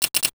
NOTIFICATION_Glass_08_mono.wav